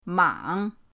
mang3.mp3